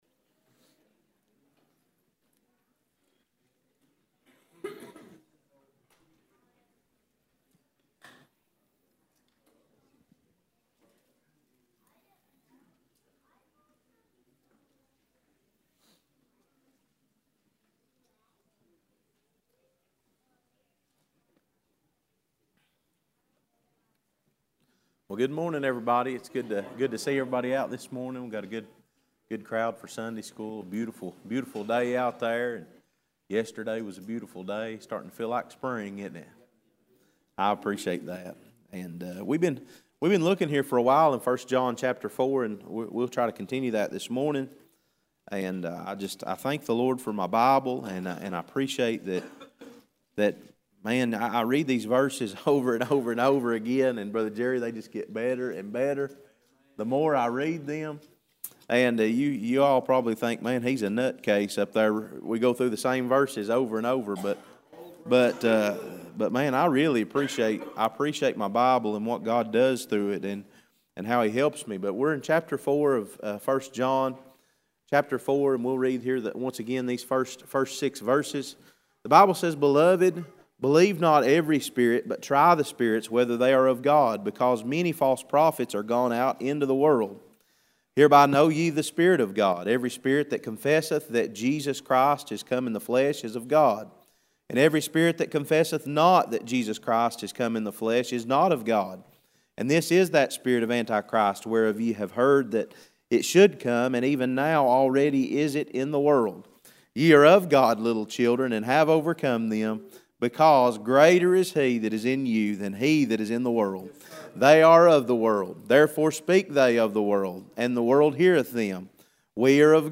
Passage: 1 John 4:1-6 Service Type: Sunday School